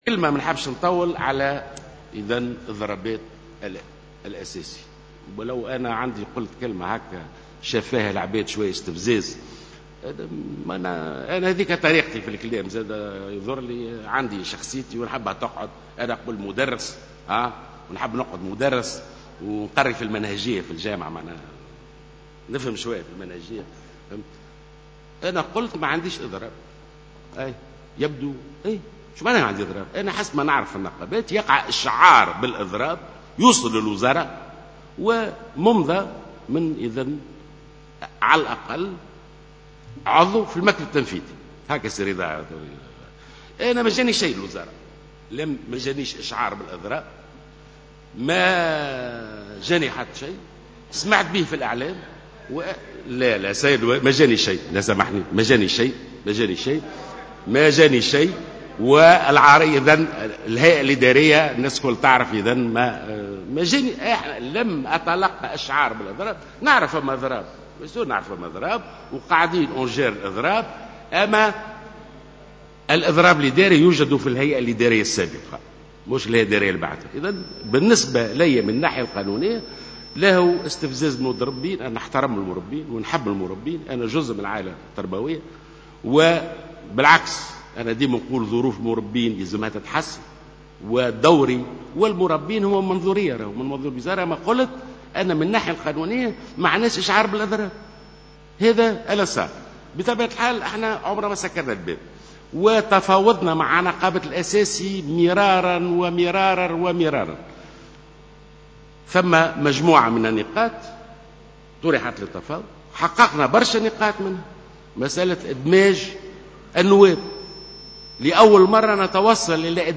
وأكد وزير التربية في جلسة عامة بمجلس النواب أن مطالب نقابة التعليم الأساسي مشروعة و لكن لا يمكن الاستجابة لها نظرا للوضع الاقتصادي الصعب، على حد تعبيره.